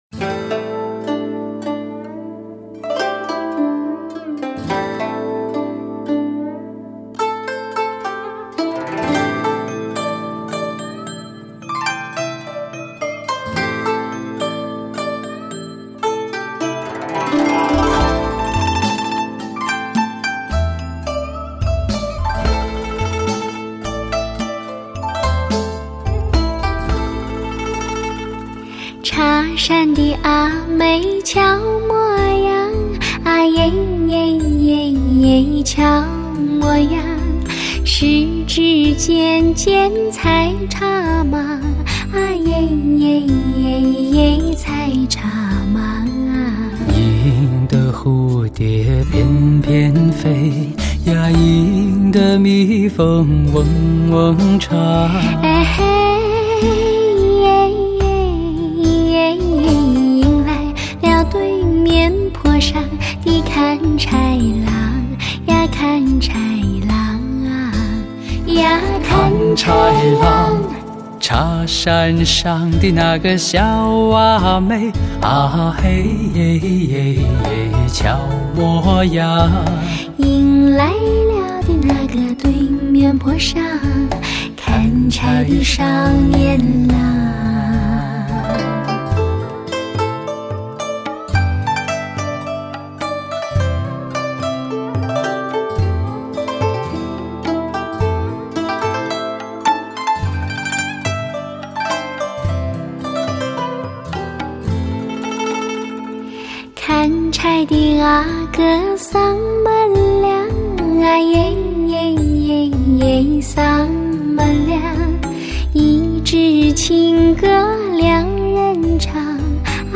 男女人声质感更为出色，音场定位更为明确，千锤百炼，重量级巨献。